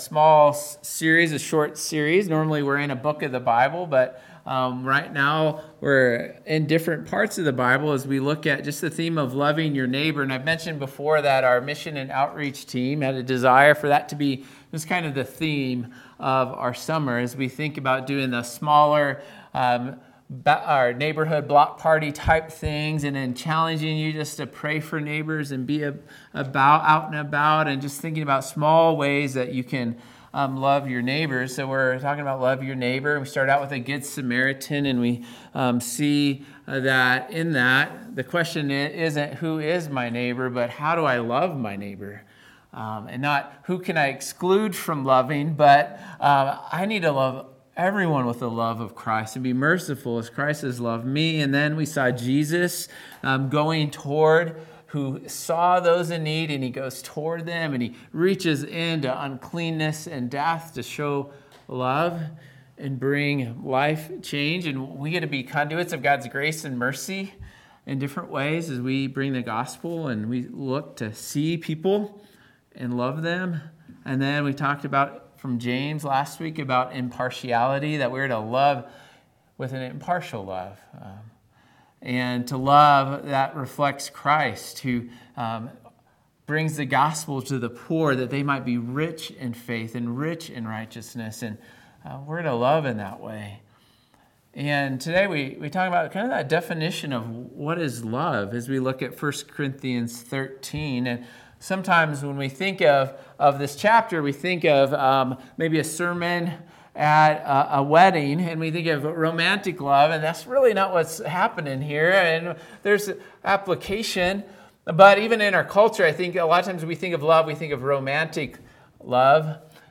Sermons by Calvary Church Derby Hill